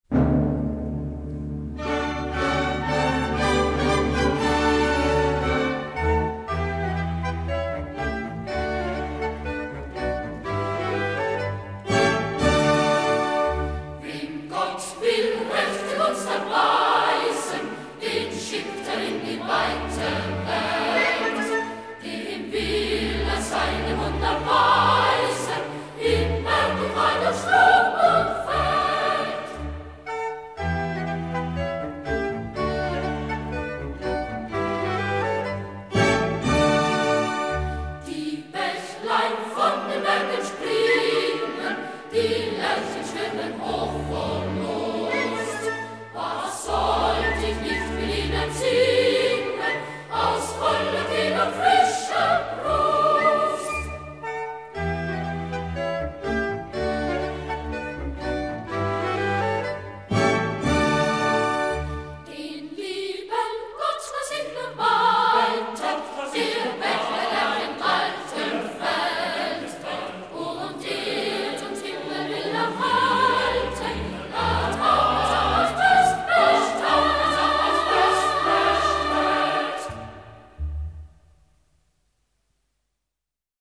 Unter den vielen Liedern, die nach Gedichten Eichendorffs entstanden sind, ist "Wem Gott will rechte Gunst erweisen" sicherlich das bekannteste. 1833 von dem Schweizer Musiklehrer Friedrich Theodor Fröhlich (1803-1836) komponiert, wurde es 1849 auch in das "Deutsche Commersbuch", den Liederschatz der Burschenschaften, aufgenommen und dadurch zu einem wirklichen Volkslied.
Eine Aufnahme mit den "Regensburger Domspatzen" (Deutsche Grammophon GmbH, 1962)